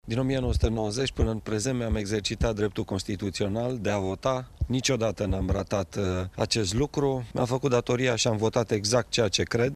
Prefectul județului Brașov, Marian Rasaliu, a votat la primele ore ale dimineții. El a menționat că din 1990 și până acum, nu a lipsit de la nici un scrutin: